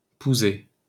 Pouzay (French pronunciation: [puzɛ]